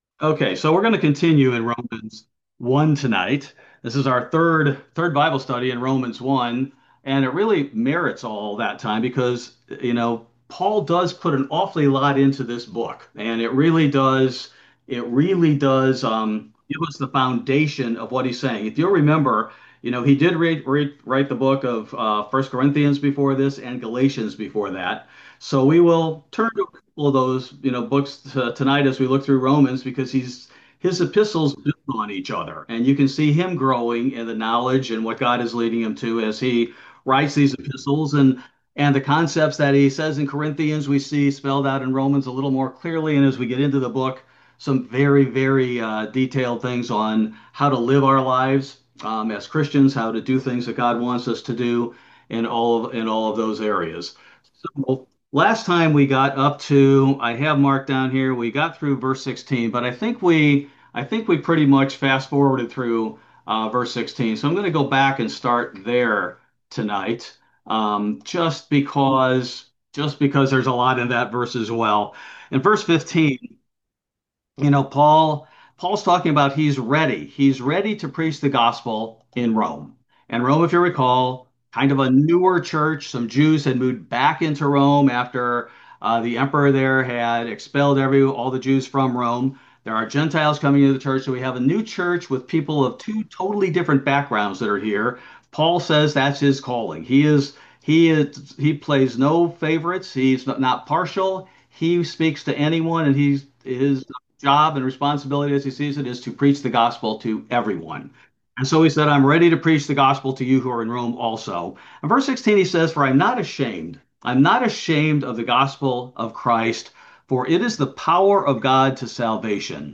Bible Study: June 25, 2025